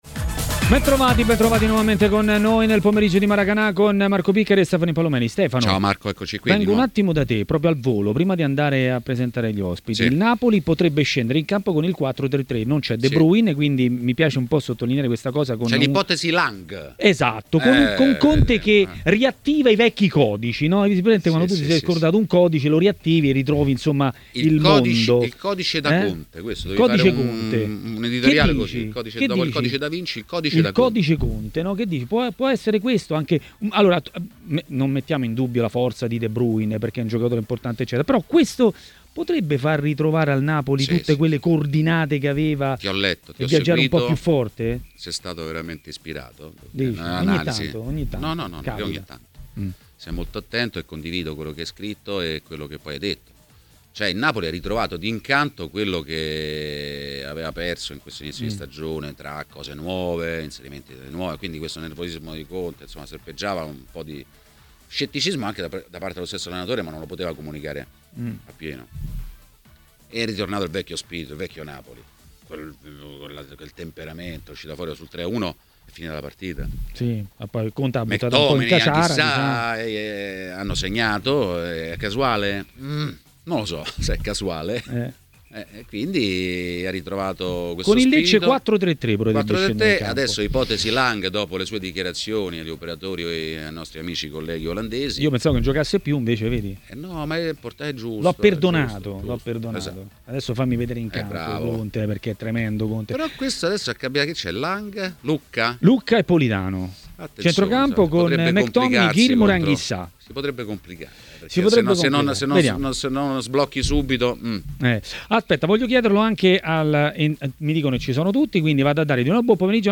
è intervenuto a TMW Radio, durante Maracanà, per parlare di Juventus